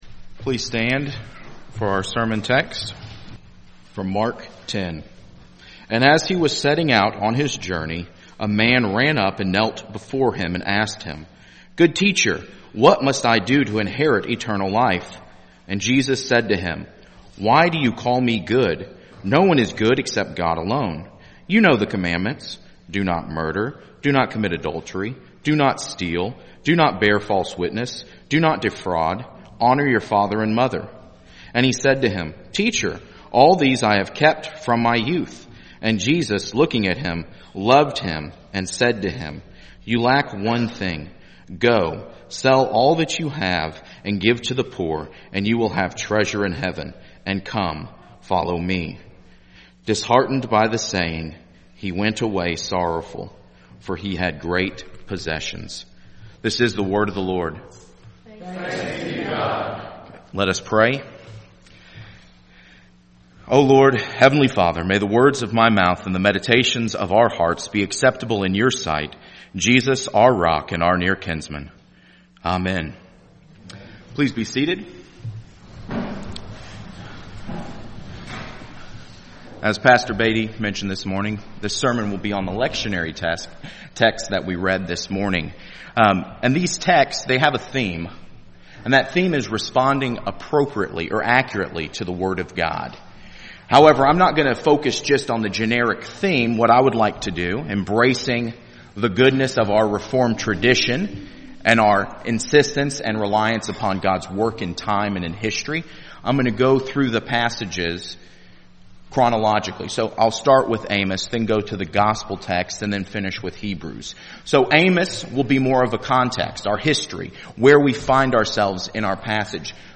Sermons Oct 14 2018 “The Great Decision